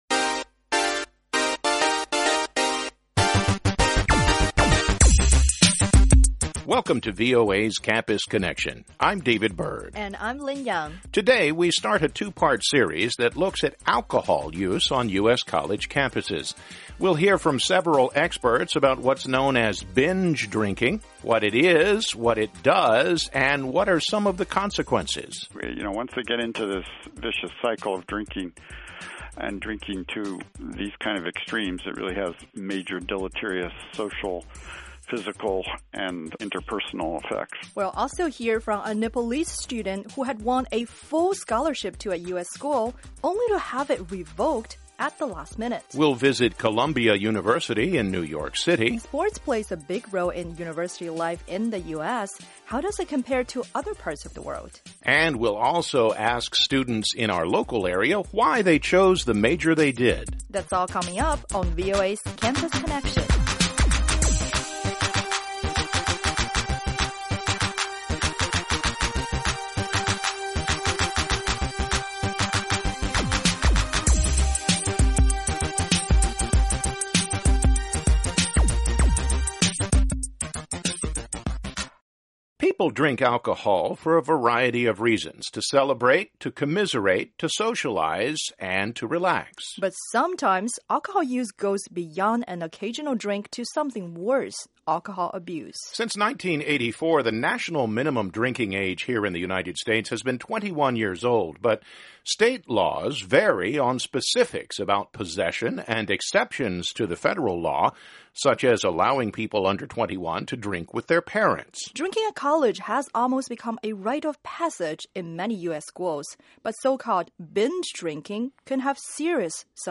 In this episode, we talk to a panel of experts about alcohol use and abuse on U.S. college campuses.